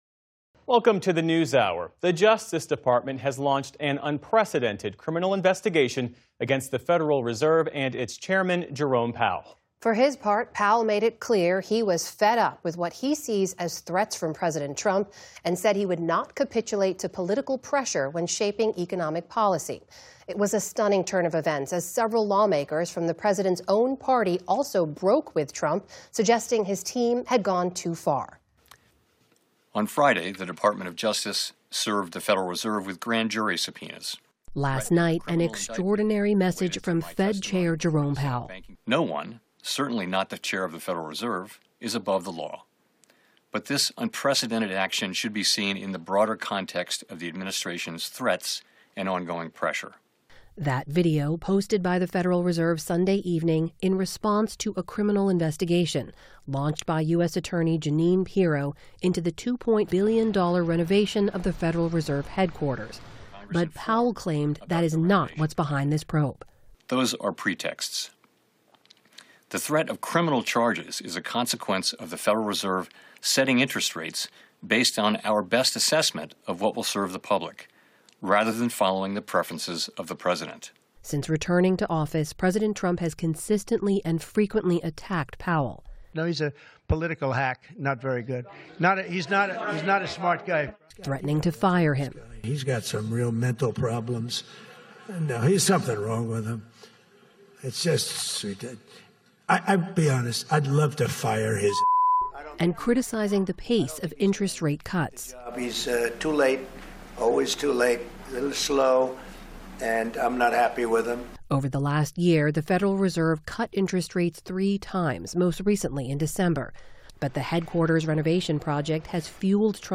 Amna Nawaz reports.